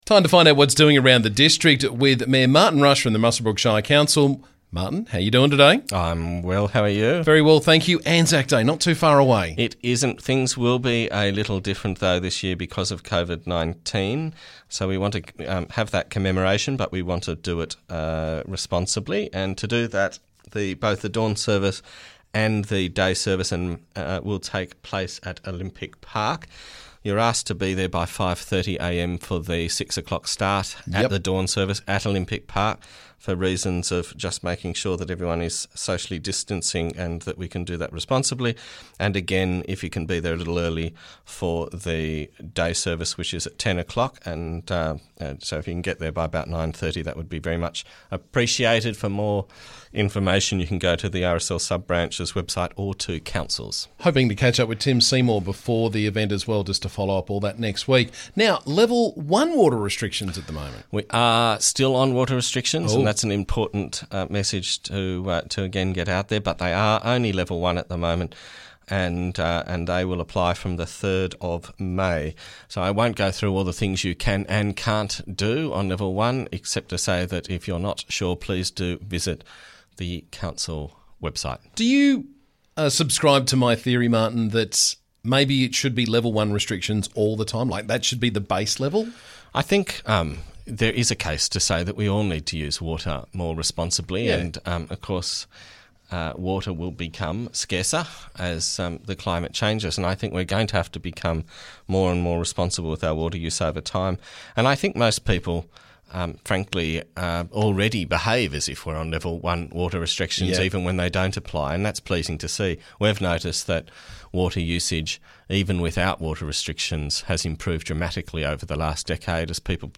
Muswellbrook Shire Council Mayor Martin Rush joined me to talk about the latest from around the district.